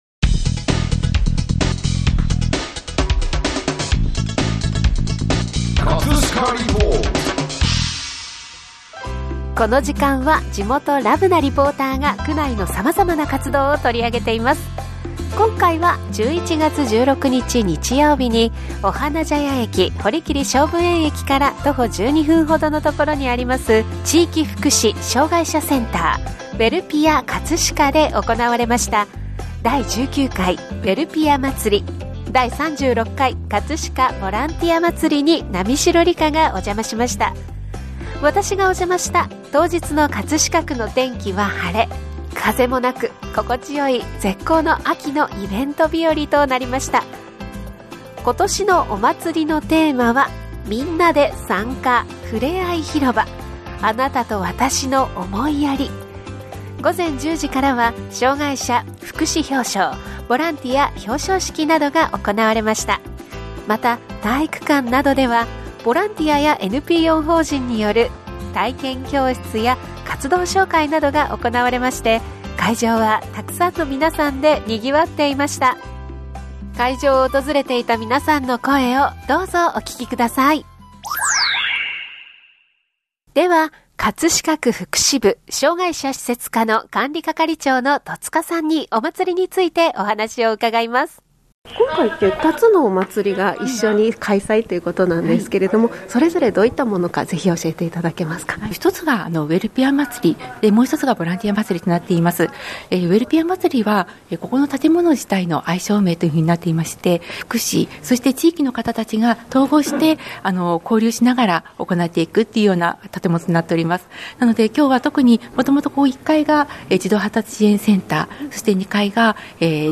【葛飾リポート】 葛飾リポートでは、区内の様々な活動を取り上げています。
今年のおまつりのテーマは みんなで参加 ふれあい広場～あなたとわたしの思いやり～ 午前10時から障害者福祉表彰・ボランティア表彰式、また体育館などでは、車いすやボッチャなどの体験コーナーやボランティアやNPO法人による体験教室や活動紹介などが行われ、会場はたくさんの皆さんでにぎわっていました。 ＜障害者作品展 26団体が出展＞ ＜出店団体のみなさん＞ 会場を訪れていた皆さんの声をどうぞお聴きください。